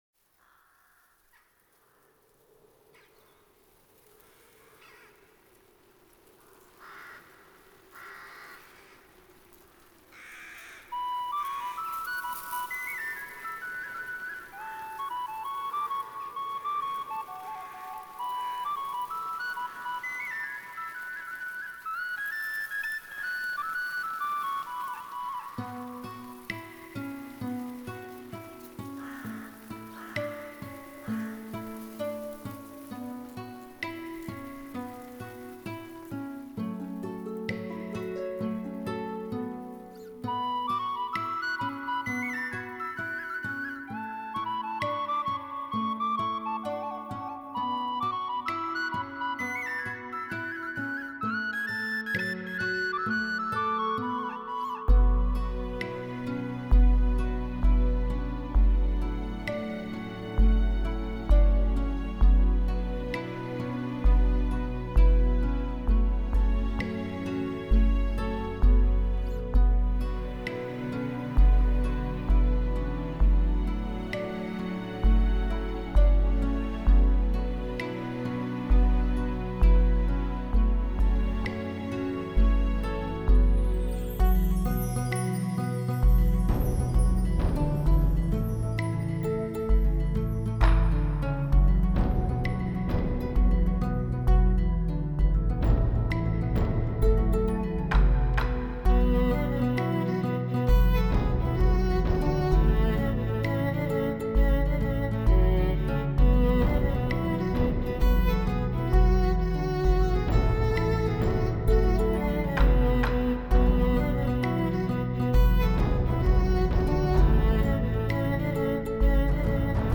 New age Нью эйдж Музыка релакс Relax Музыка нью эйдж